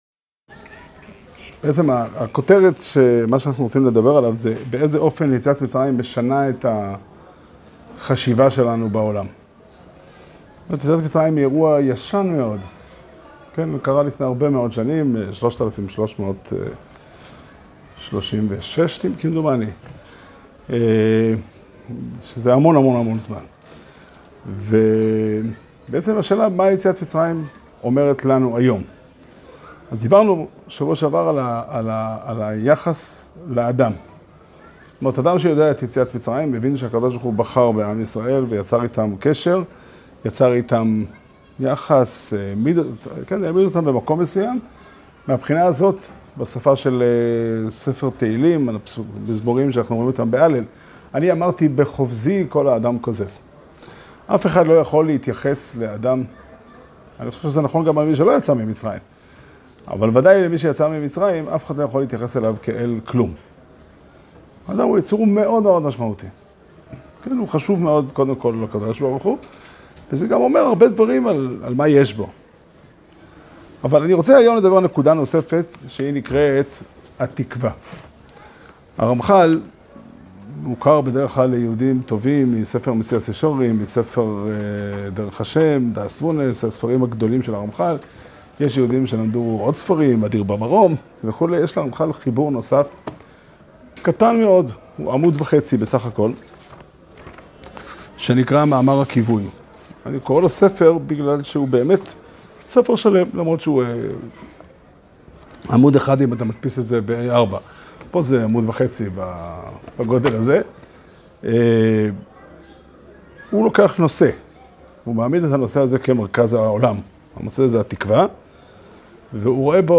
שיעור שנמסר השבוע בבית המדרש 'פתחי עולם' בתאריך כ"ג אדר ב' תשפ"ד